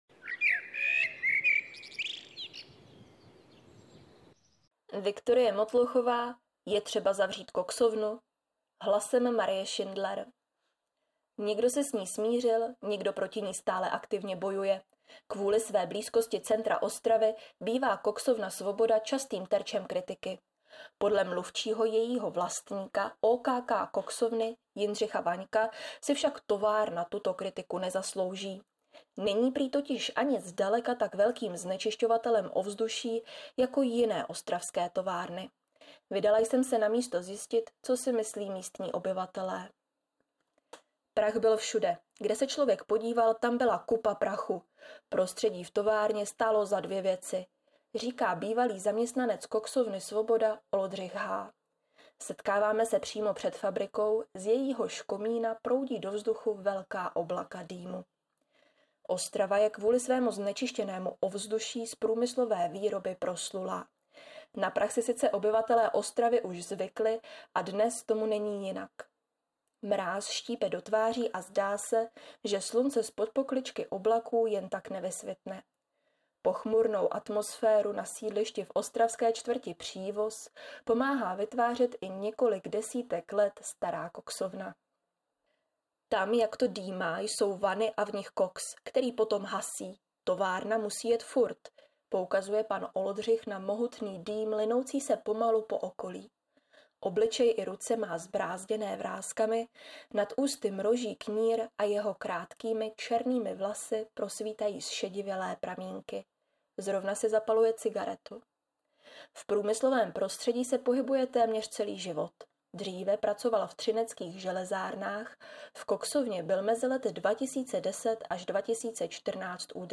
Vydala jsem se na místo zjistit, co si myslí místní obyvatelé.